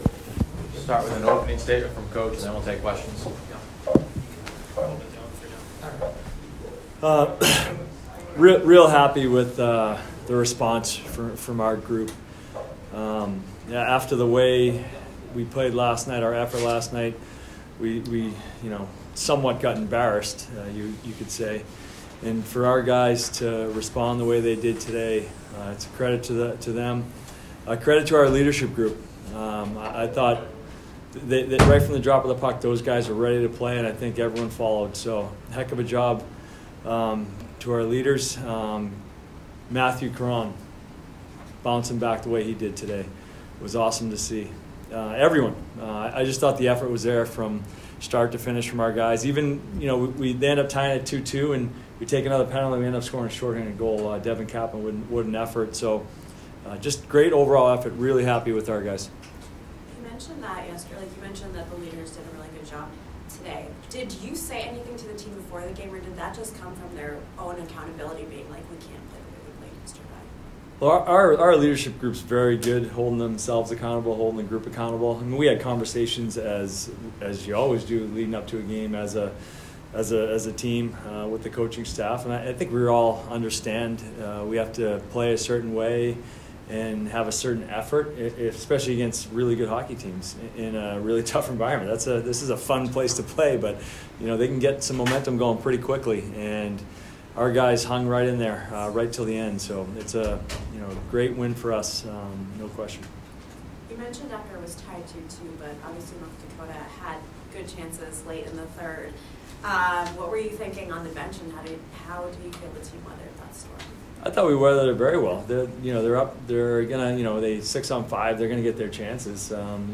Men's Ice Hockey / North Dakota Postgame Press Conference (10-26-24)